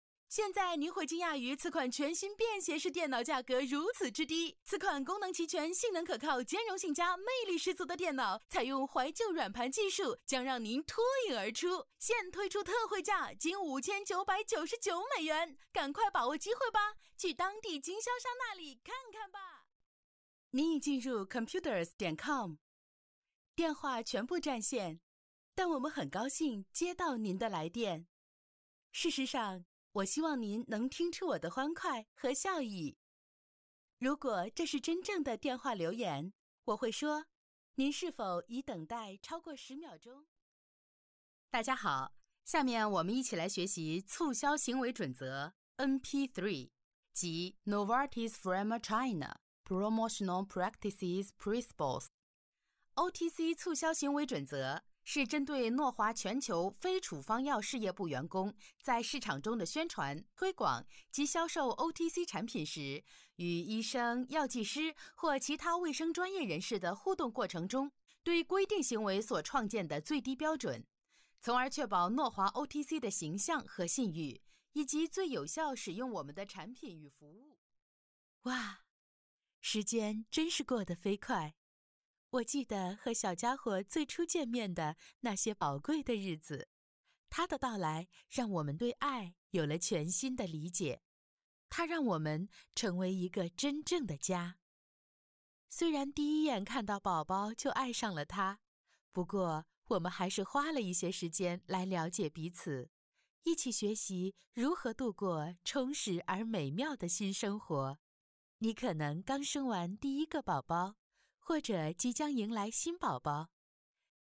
Weiblich